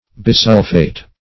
Search Result for " bisulphate" : The Collaborative International Dictionary of English v.0.48: Bisulphate \Bi*sul"phate\, n. [Pref. bi- + sulphate.]